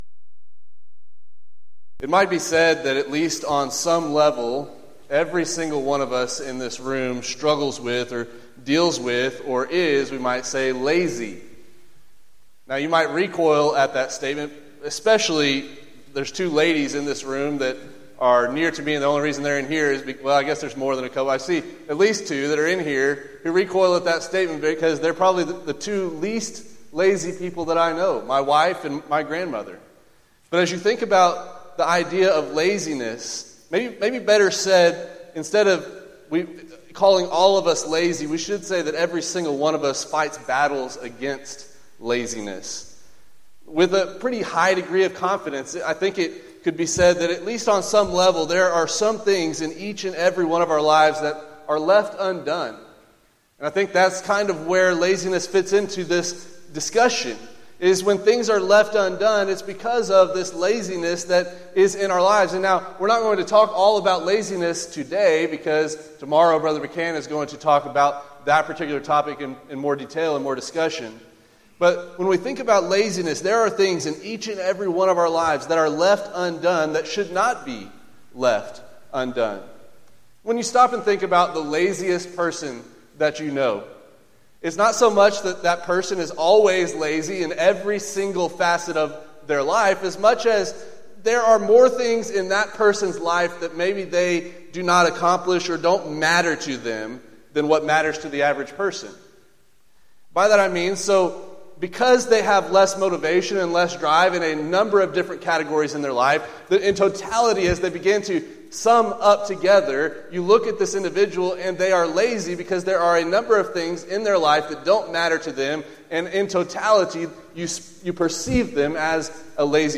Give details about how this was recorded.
Event: 6th Annual Southwest Spiritual Growth Workshop